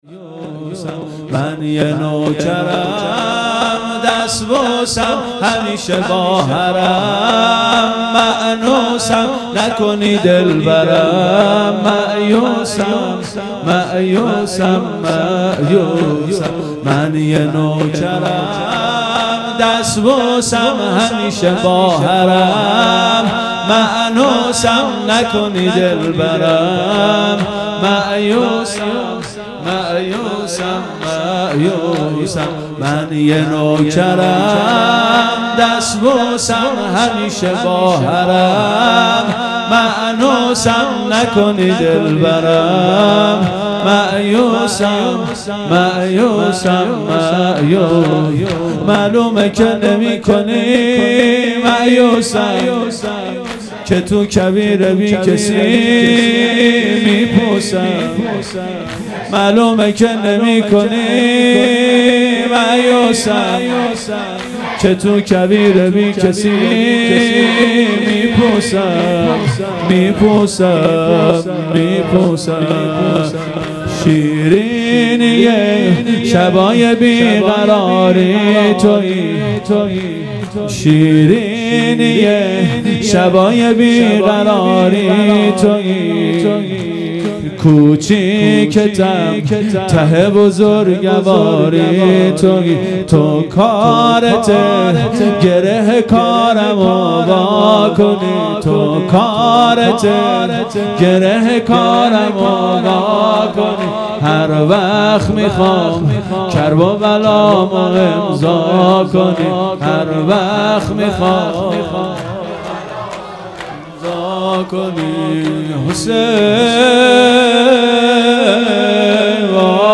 شور | من یه نوکرم دسبوسم مداح